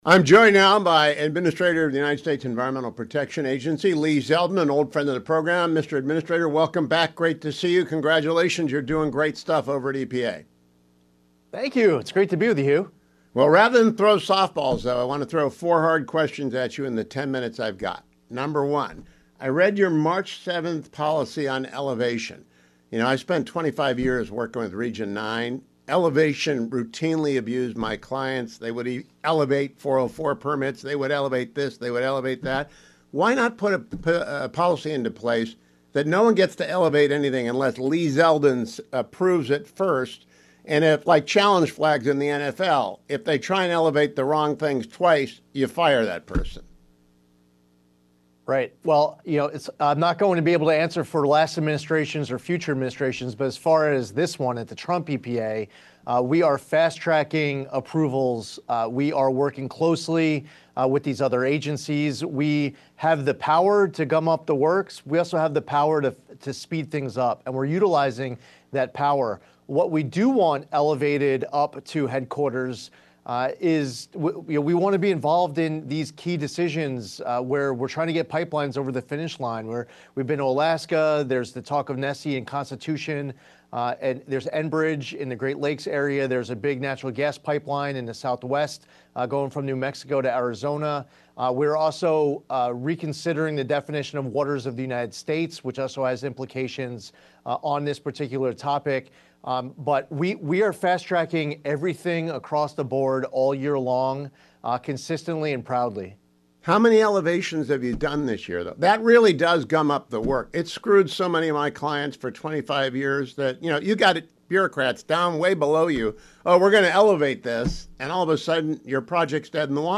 EPA Administrator Lee Zeldin joined Hugh today to discuss changes made to the agency’s “elevation” policy and how the Administrator could make the unwieldy agency far more responsive to permit seekers and fair to targets of alleged illegal or unpermitted activity by the agency’s Enforcement Division: